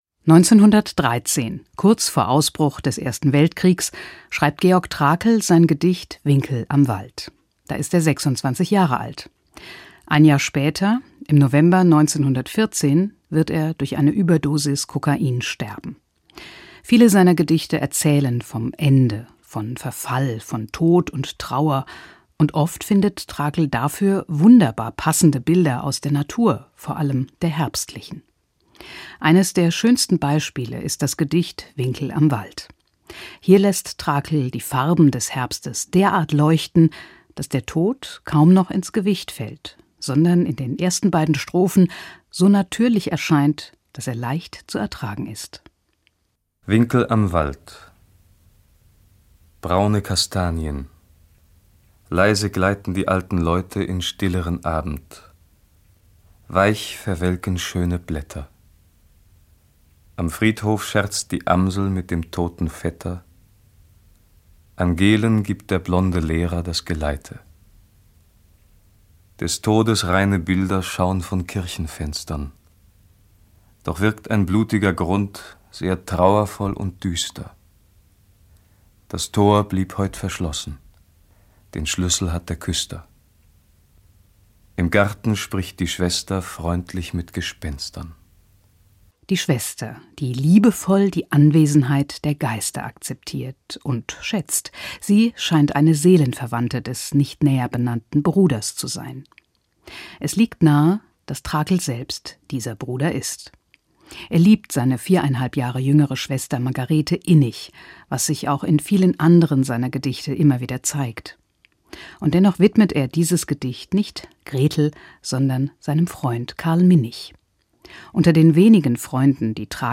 gedicht-winkel-am-wald-georg-trakl.mp3